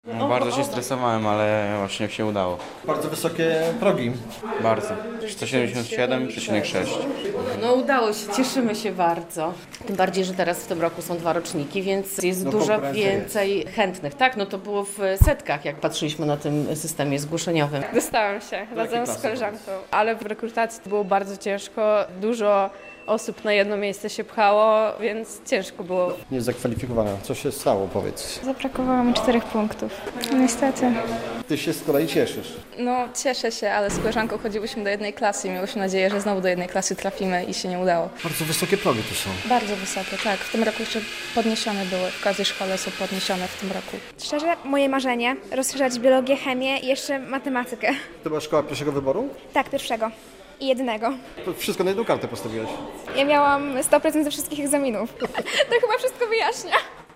Uczniowie, z którymi rozmawialiśmy, przyznają, że tegoroczna rekrutacja do najpopularniejszych szkół, była bardzo wymagająca, bo placówki mocno wyśrubowały progi punktowe uprawniające do przyjęcia.